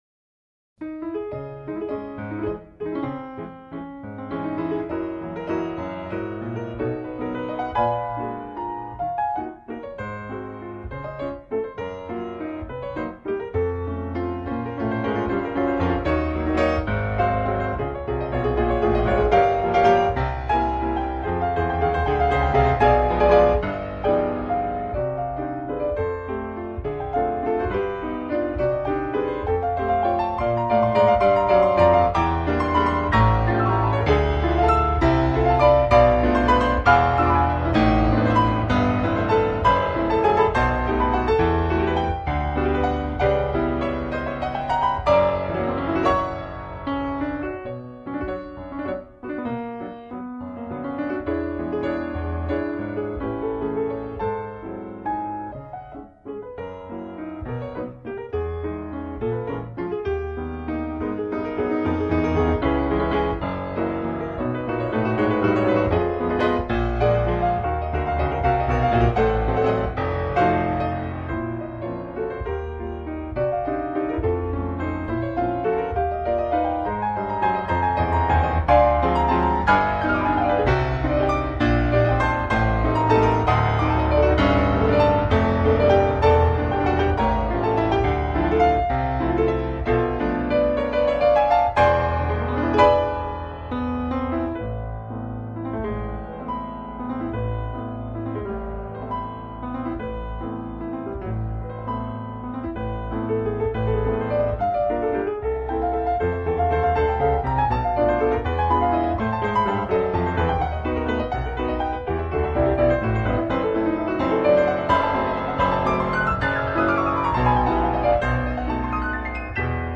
难度圆舞曲,迸发出炙热的生命力